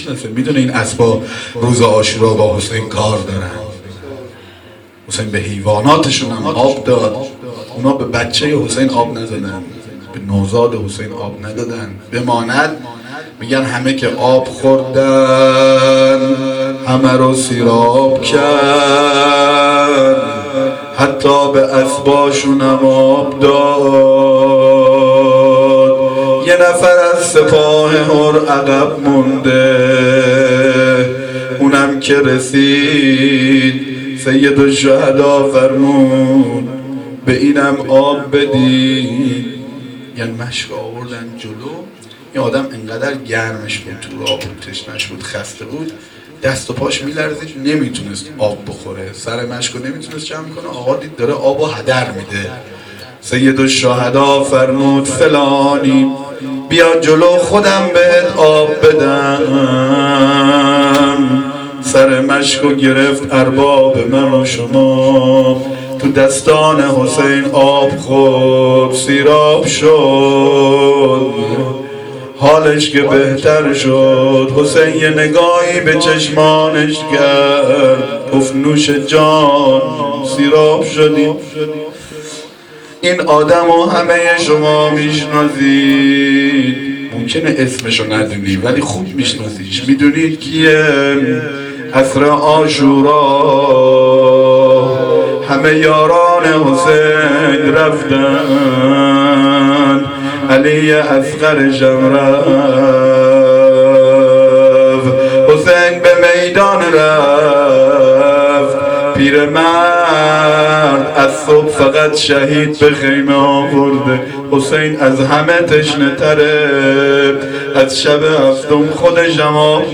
سخنرانی - بخش4.m4a